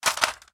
large-weapon / collide / bounce03hl.ogg
bounce03hl.ogg